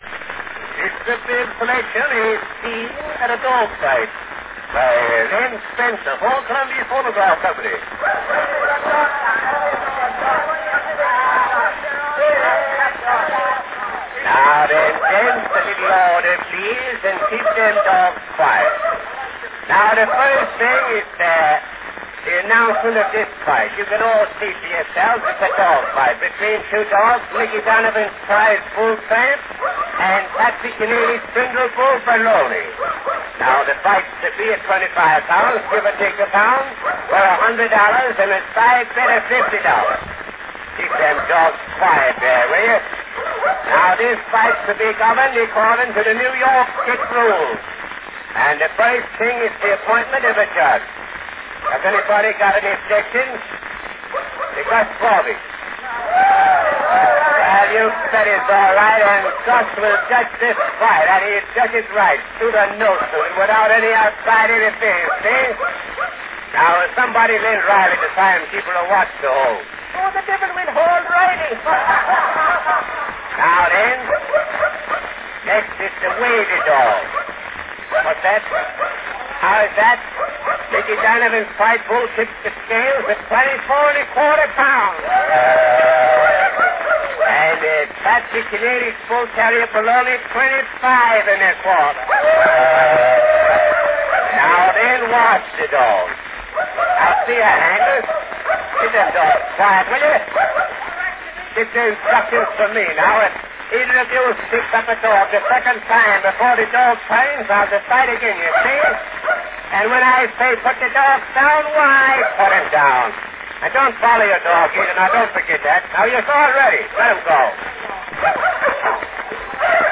An unusual Scene at a Dog Fight recording from 1900.
Category Descriptive selection
Performed by Len Spencer
Spencer uses the same comic technique in laying down the event's rules – although here the dogs grate his nerves (and get in the last 'word').
Of course, all the dog sounds are by imitation.